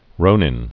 (rōnĭn)